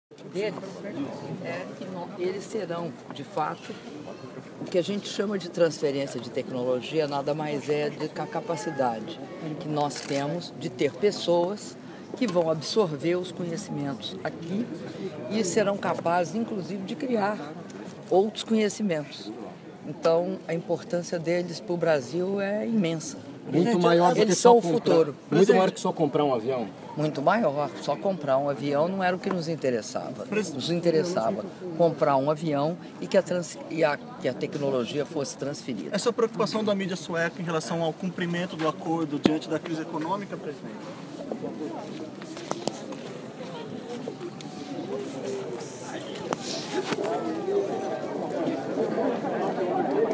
Áudio da entrevista concedida pela Presidenta da República, Dilma Rousseff, durante visita à SAAB - Estocolmo/Suécia(0min50s)